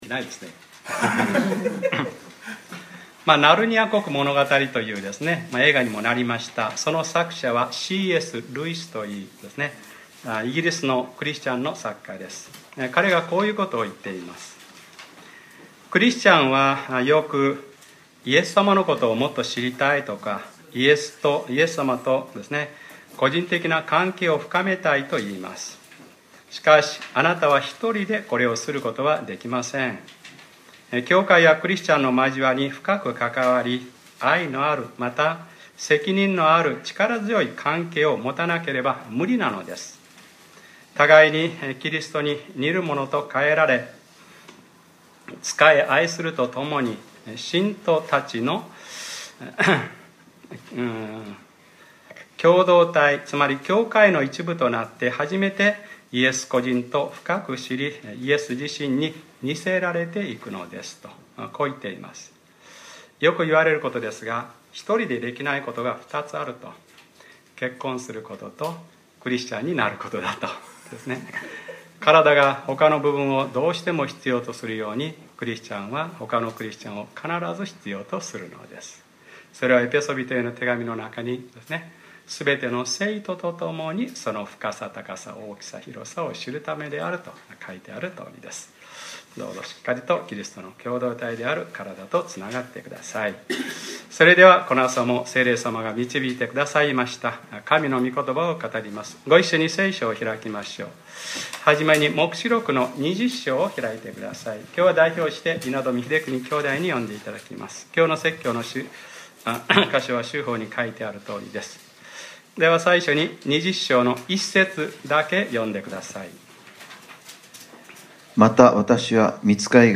2014年7月27日（日）礼拝説教 『黙示録ｰ３２：千年王国（メシア王国）』 | クライストチャーチ久留米教会